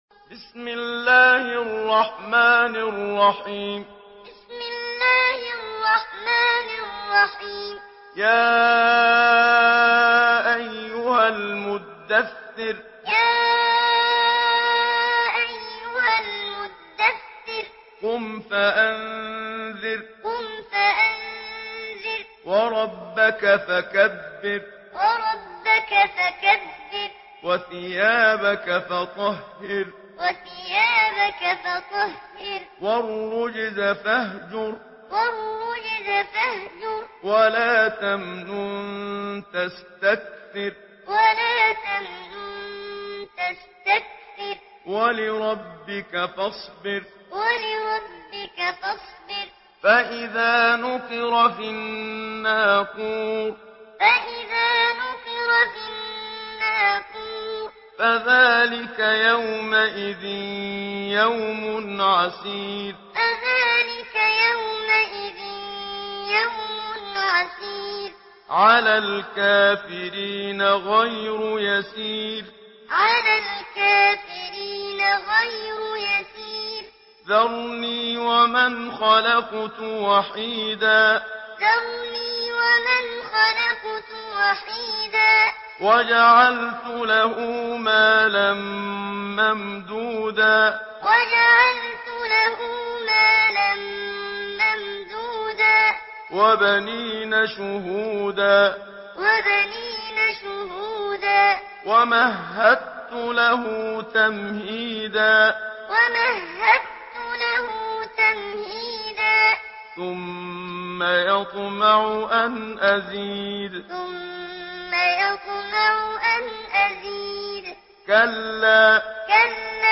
Surah আল-মুদ্দাস্‌সির MP3 in the Voice of Muhammad Siddiq Minshawi Muallim in Hafs Narration
Surah আল-মুদ্দাস্‌সির MP3 by Muhammad Siddiq Minshawi Muallim in Hafs An Asim narration.